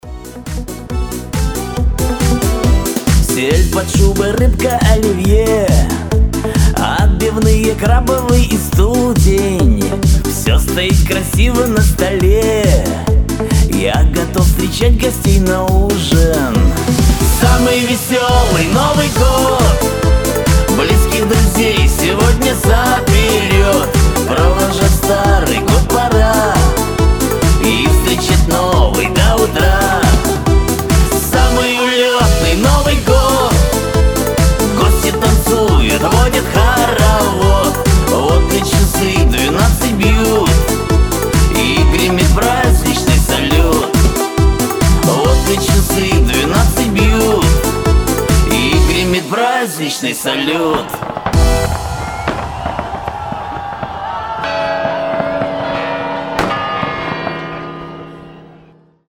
• Качество: 320, Stereo
веселые
добрые
праздничные
застольные